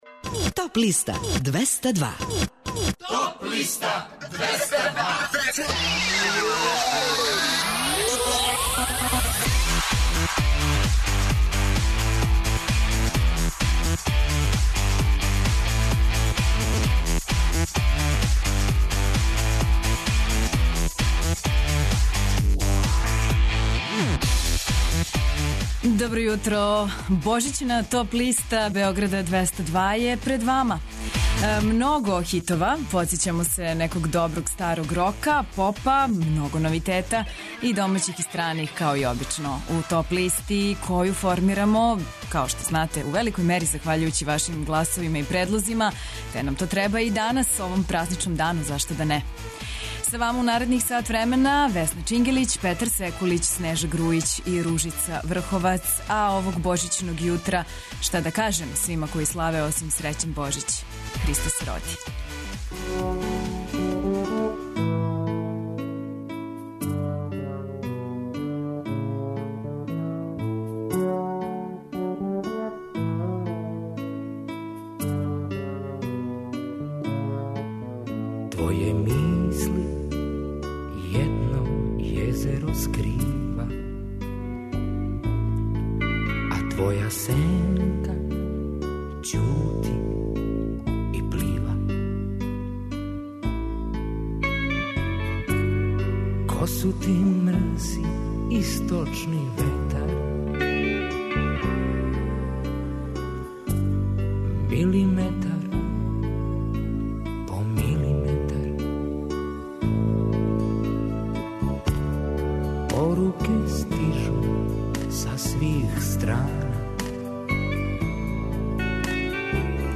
У овонедељном издању Топ листе у 2016. години слушајте новогодишње и божићне музичке нумере, иностране и домаће новитете, као и композиције које су се нашле на подлисти лектира, класика, етно, филмскe музикe...